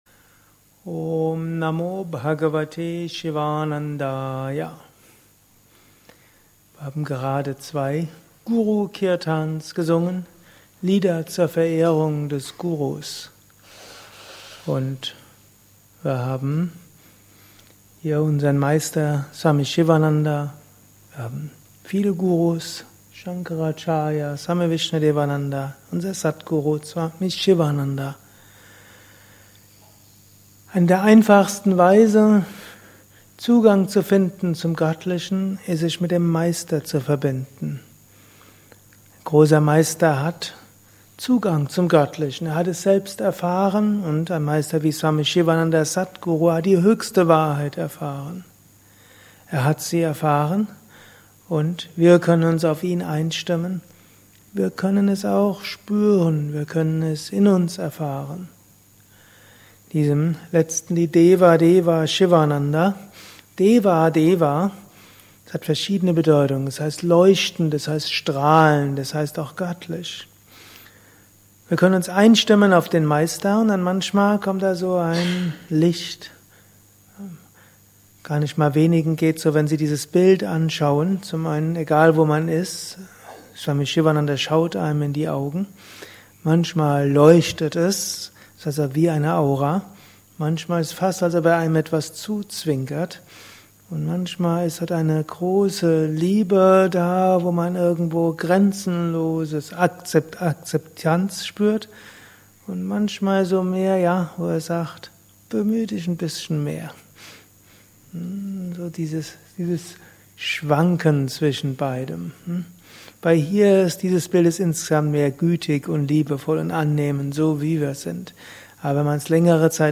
Kurzvorträge
gehalten nach einer Meditation im Yoga Vidya Ashram Bad Meinberg.